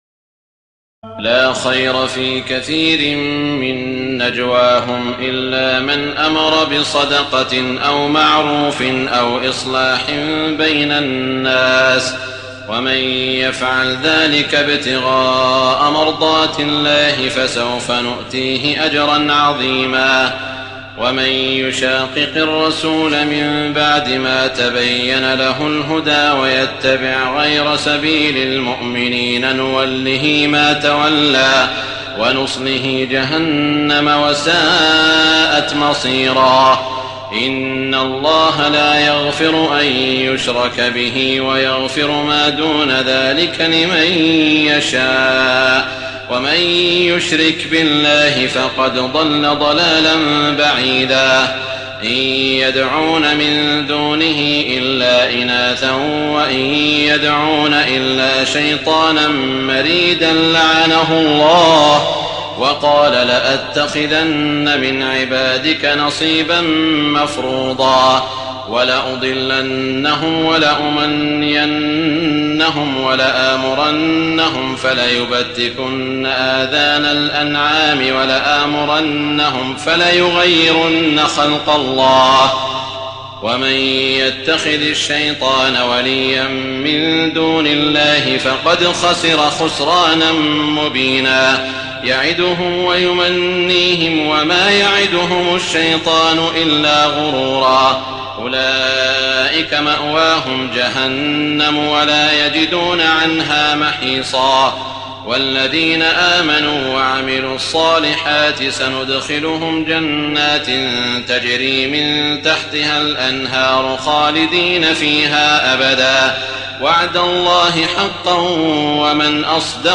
تراويح الليلة الخامسة رمضان 1419هـ من سورتي النساء (114-176) و المائدة (1-11) Taraweeh 5 st night Ramadan 1419H from Surah An-Nisaa and AlMa'idah > تراويح الحرم المكي عام 1419 🕋 > التراويح - تلاوات الحرمين